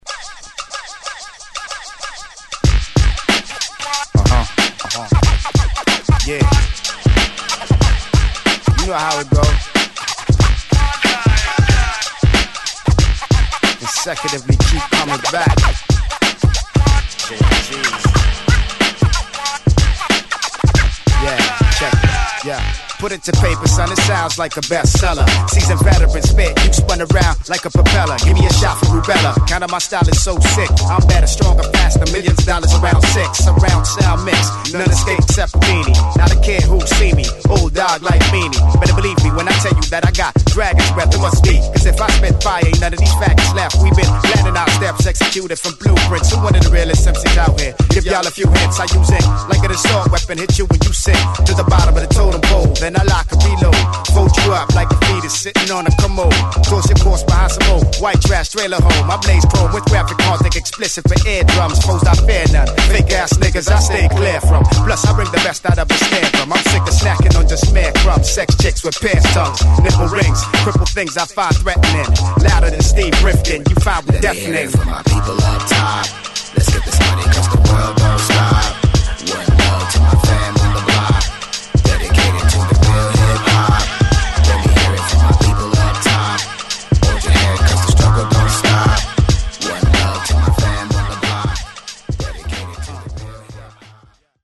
ホーム HIP HOP UNDERGROUND 12' & LP D